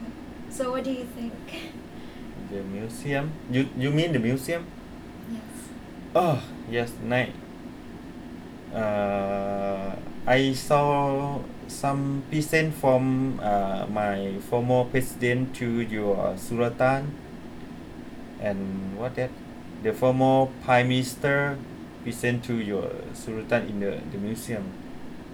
S1 = Brunei female S2 = Laos male
Intended Words: present , sultan Heard as: pisen , suratan
The [r] is omitted in present , and the vowel in the first syllable is very close, especially in the first token; there are three syllables in the first token of sultan .